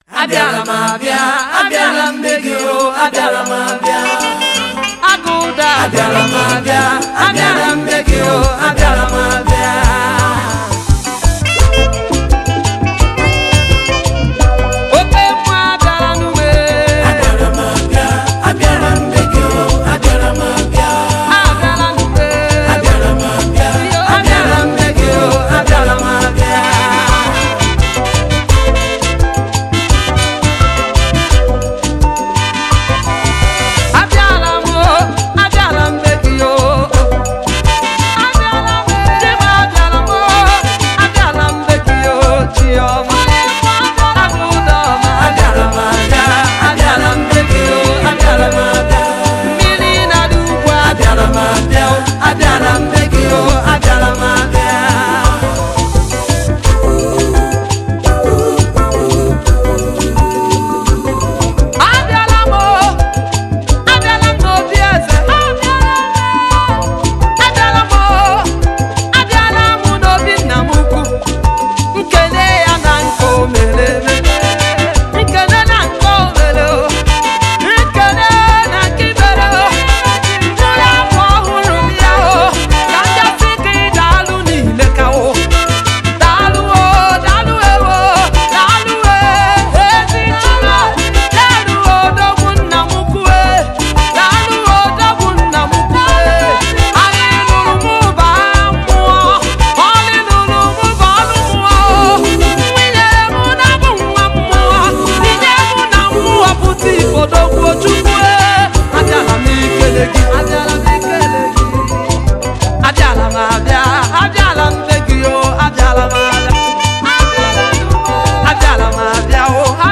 Igbo Gospel Music
soul-stirring anthem